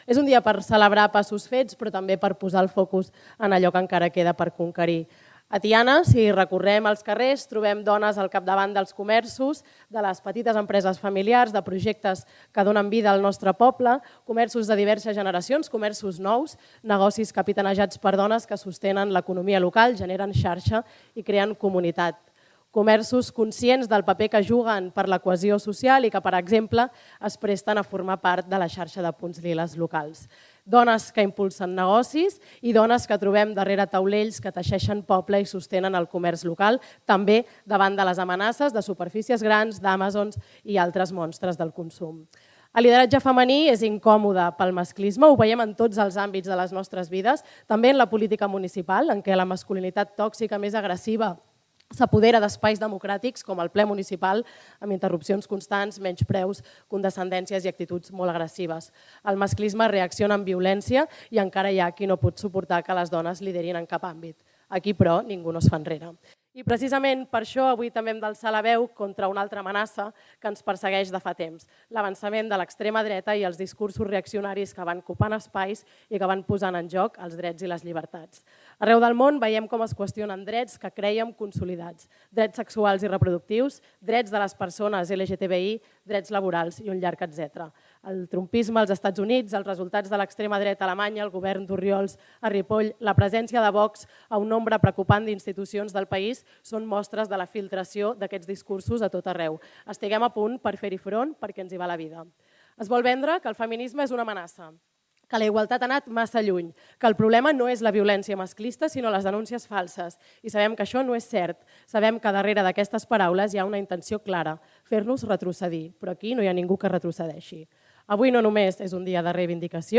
L’acte institucional del 8M, que s’ha celebrat aquest divendres 7 de març a Can Riera, ha donat el tret de sortida a les activitats programades a Tiana per aquest mes de març amb motiu del Dia de la Dona.
La regidora d’Igualtat, Eulàlia Serrat, va reflexionar sobre el què suposa per a una dona estar al capdavant de projectes comercials, emprenedors o polítics.